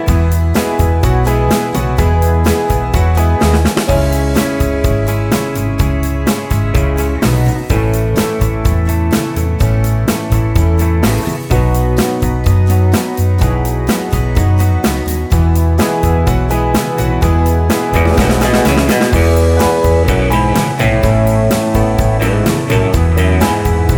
Minus Riff And Solo Guitars Easy Listening 2:26 Buy £1.50